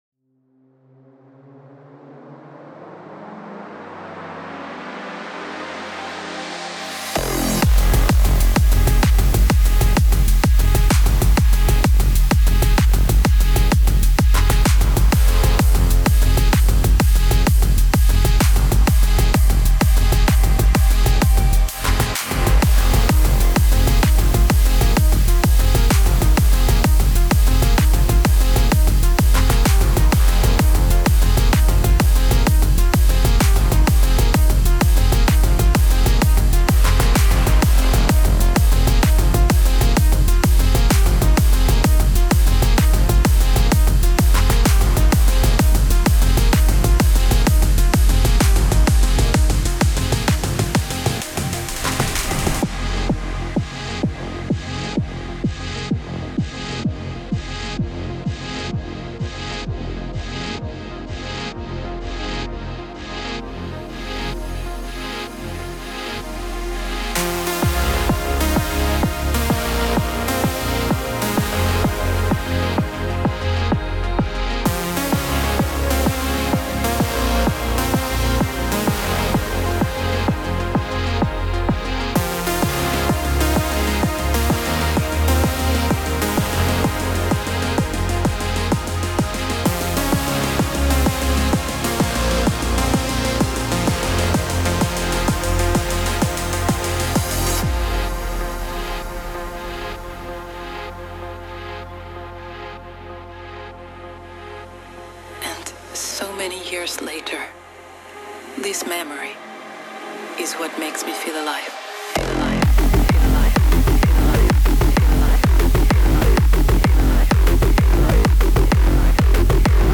موسیقی بی کلام هاوس
موسیقی بی کلام پر انرژی